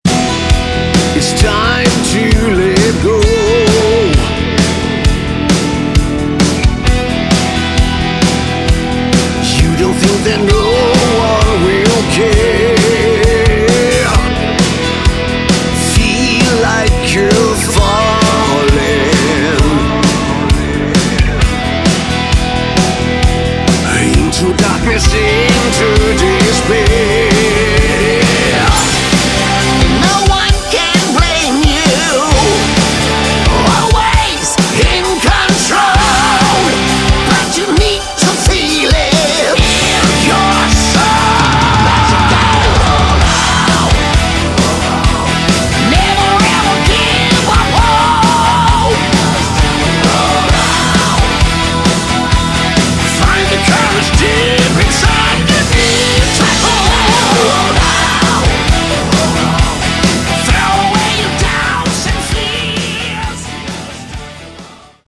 Category: Hard Rock
vocals
guitars
keyboards
bass
drums